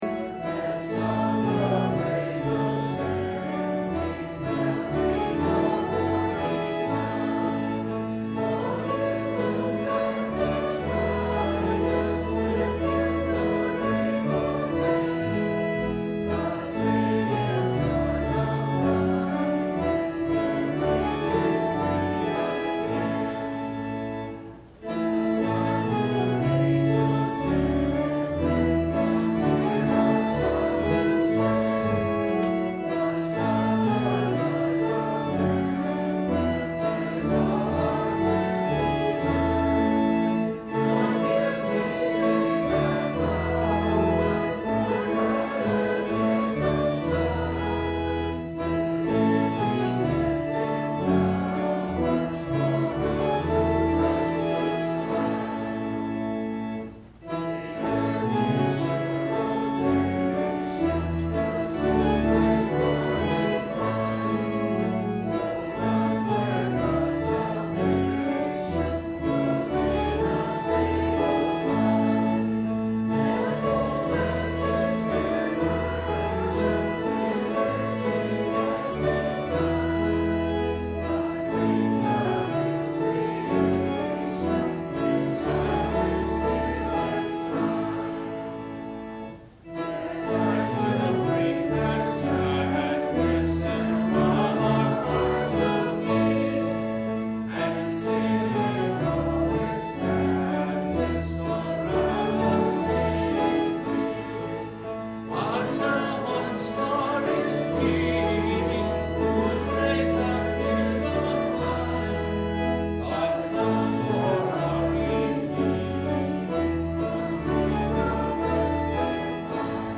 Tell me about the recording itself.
The Coming of Dawn - the Promise of Light - Sermon - December 07 2008 - Christ Lutheran Cape Canaveral